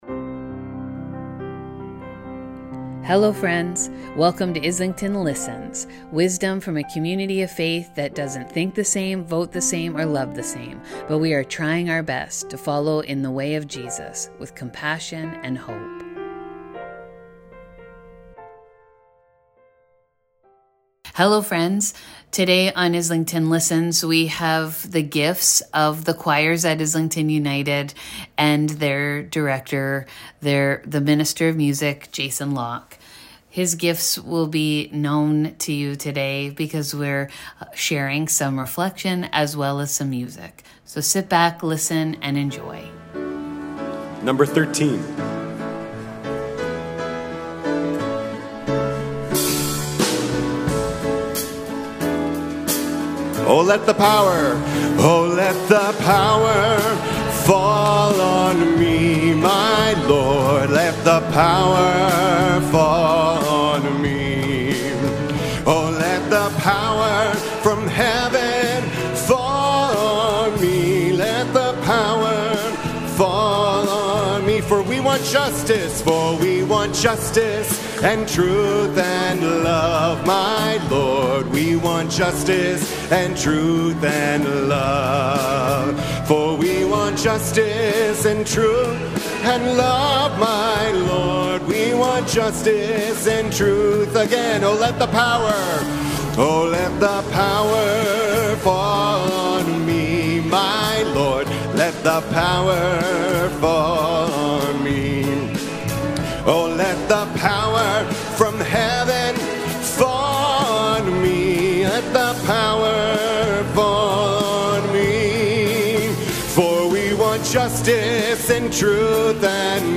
Music Sunday